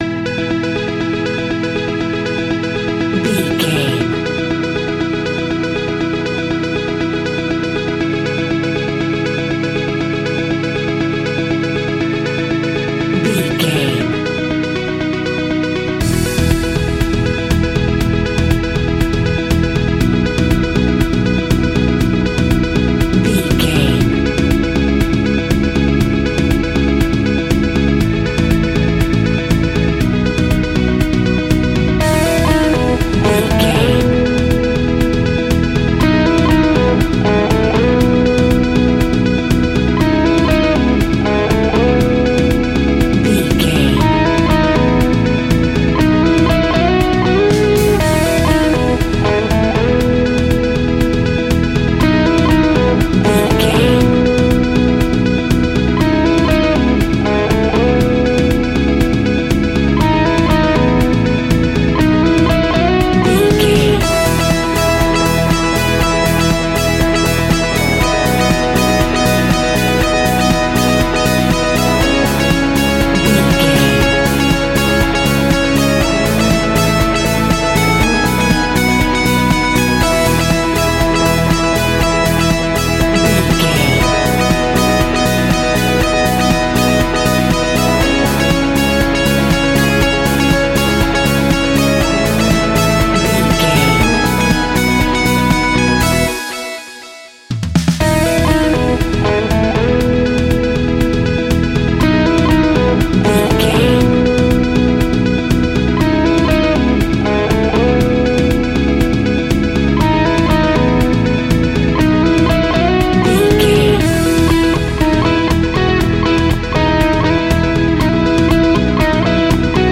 Indie Rock Business World.
Ionian/Major
energetic
uplifting
instrumentals
indie pop rock music
upbeat
groovy
guitars
bass
drums
piano
organ